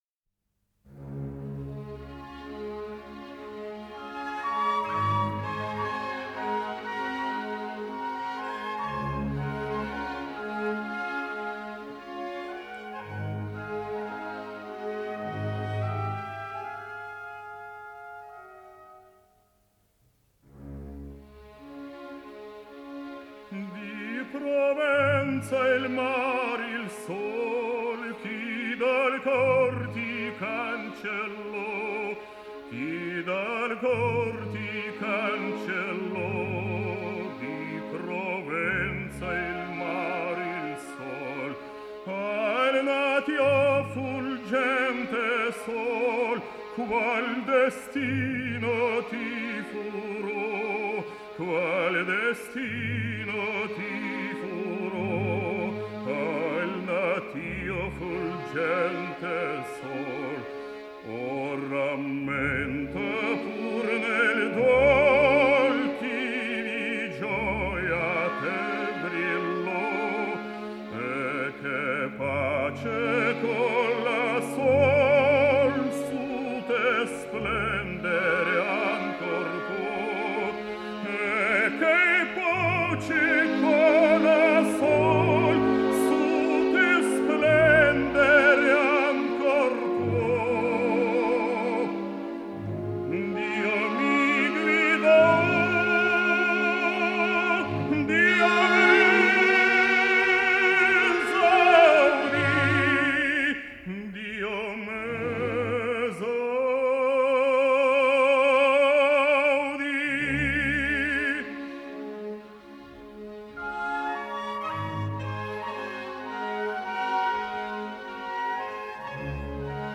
07 - Николай Кондратюк - Ария Жермона (Дж.Верди. Травиата)